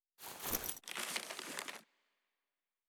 03_书店外黄昏_报童.wav